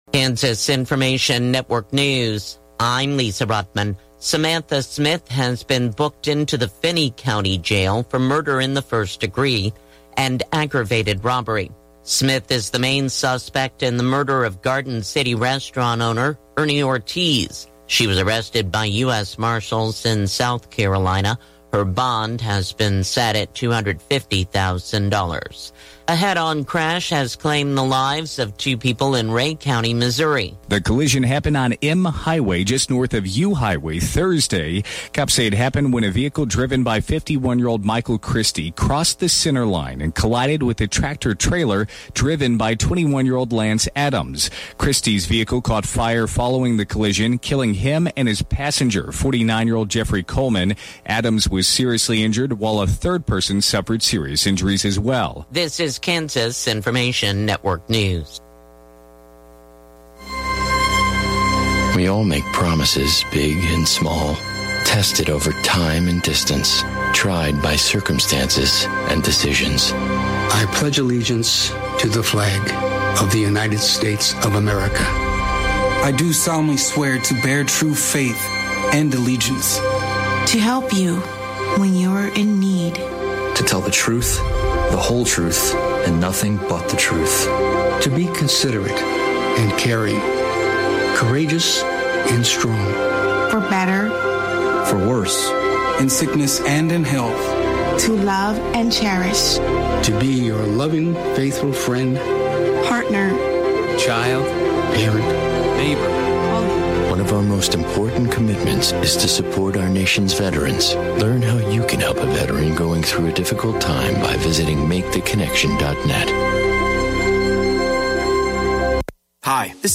Classic Hits KQNK News, Weather & Sports Update – 9/19/2023